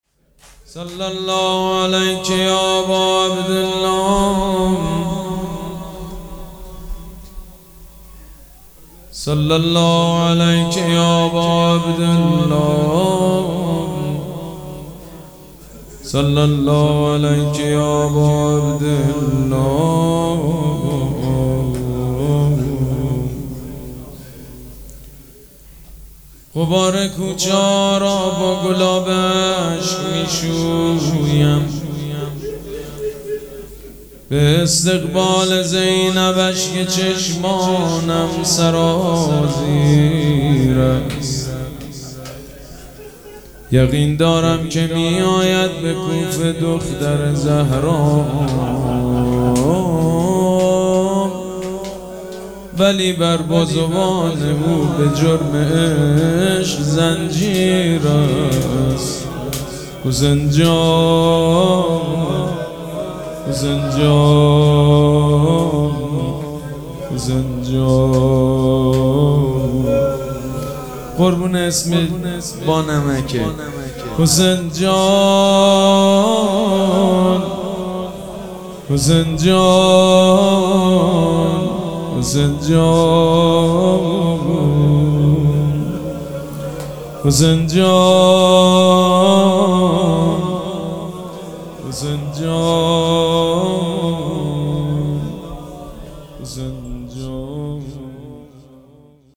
مراسم عزاداری شهادت امام محمد باقر و حضرت مسلم سلام‌الله‌علیهما
حسینیه ریحانه الحسین سلام الله علیها
روضه